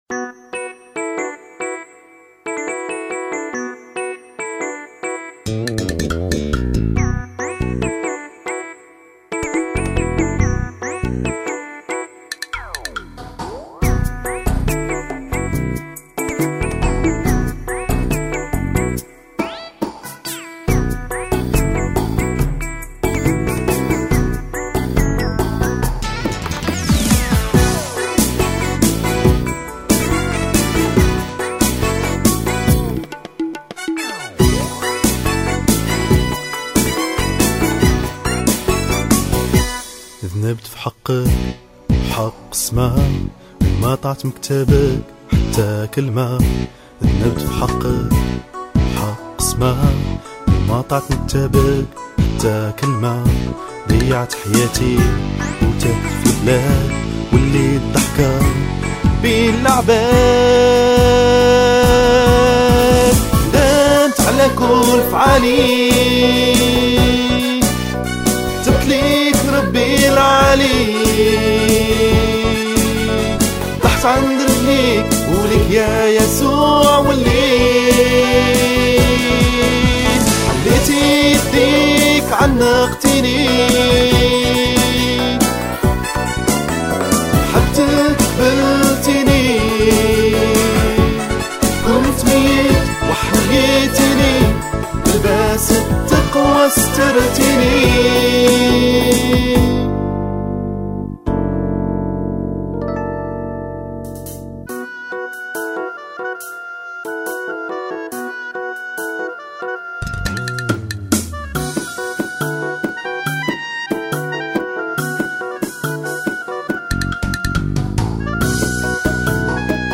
ترانيم الدرس 02